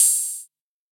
UHH_ElectroHatB_Hit-30.wav